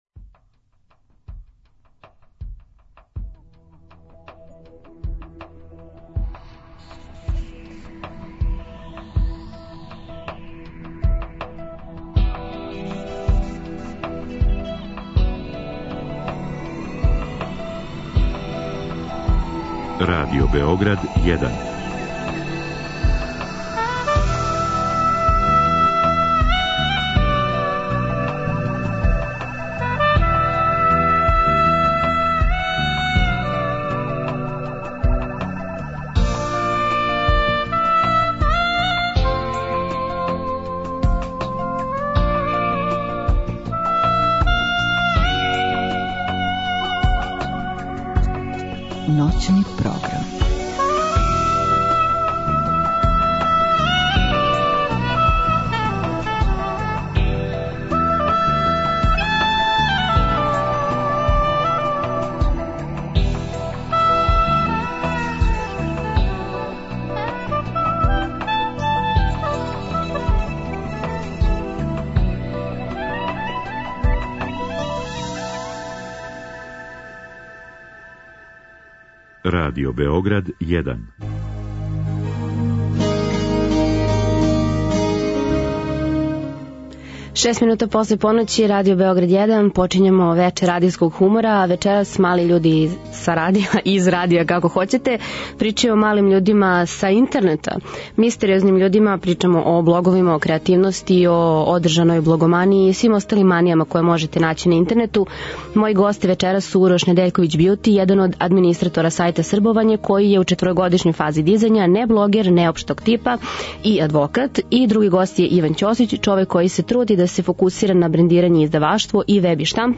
Сваке ноћи, од поноћи до четири ујутру, са слушаоцима ће бити водитељи и гости у студију